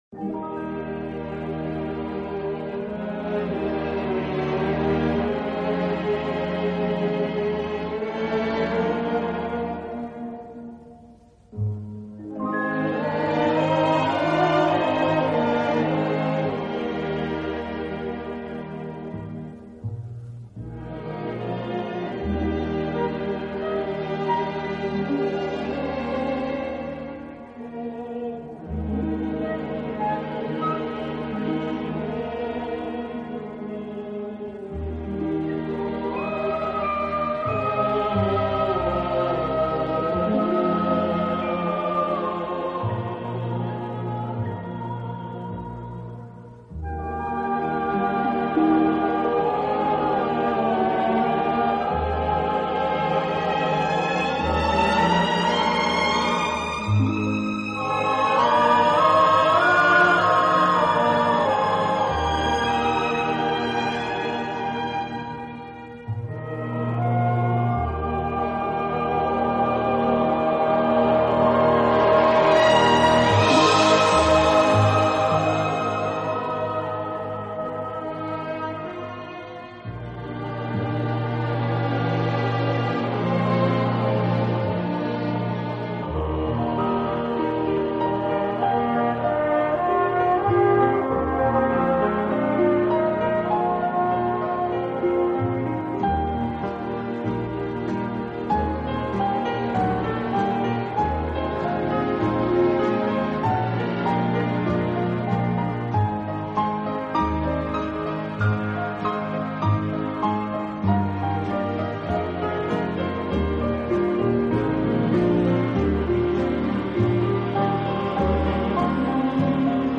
【顶级轻音乐】
好处的管乐组合，给人以美不胜收之感。